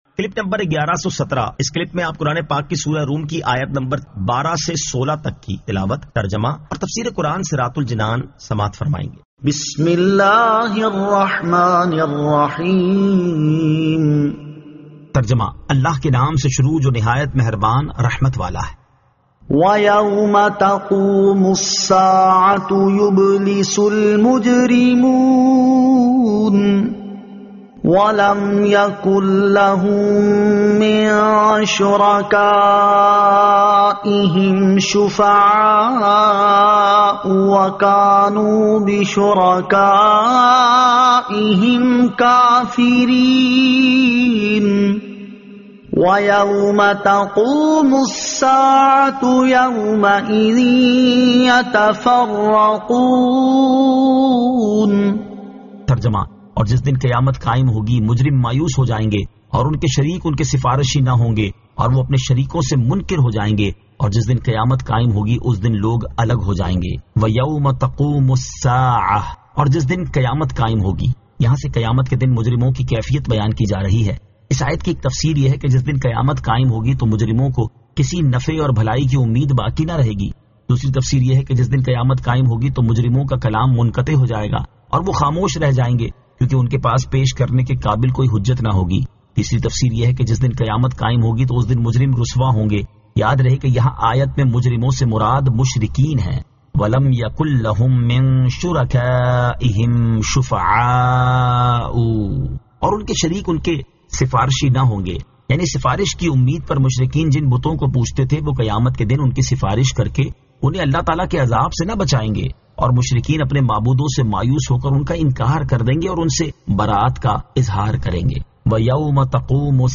Surah Ar-Rum 12 To 16 Tilawat , Tarjama , Tafseer